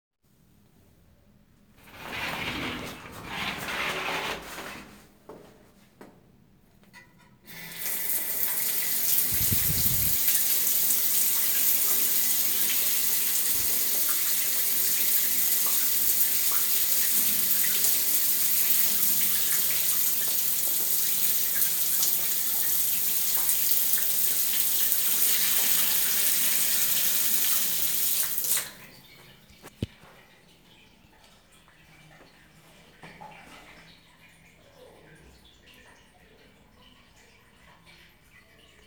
Sounds heard: closing of the shower curtain, shower turning on, water running down the drain